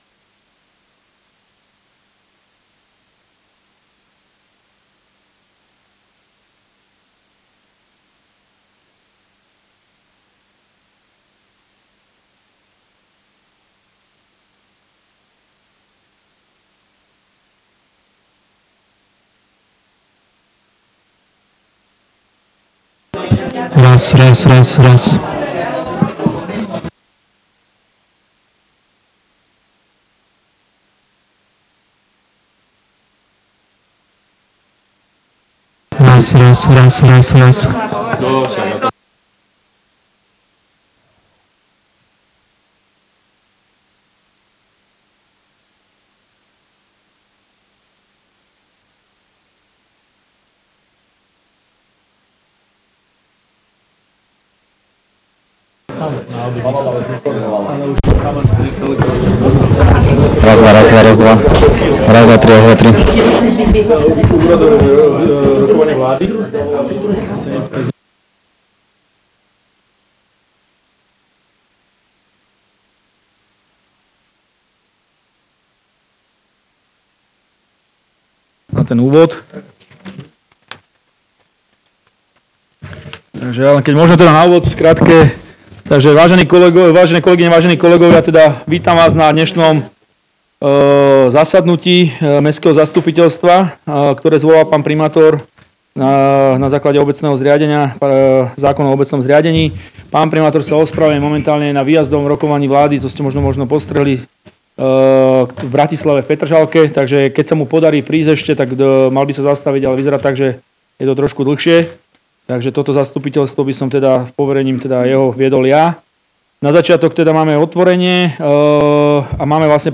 Mestské zastupiteľstvo – 14.01.2026